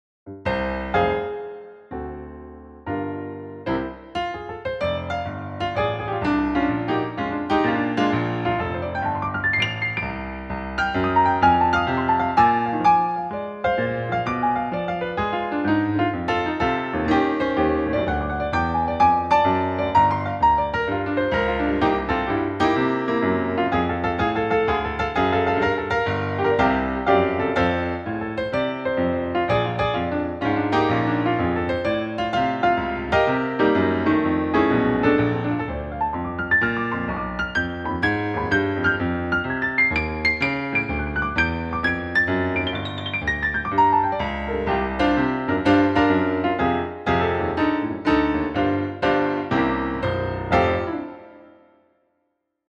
Dinnerpianist, Middagspianist, Hyggepianist, Baggrundsmusik, Klaver, Pianist, Solopianist, Solo, Jaz
blues-piano-demo-ab.mp3